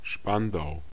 Spandau (German: [ˈʃpandaʊ]
De-Spandau.ogg.mp3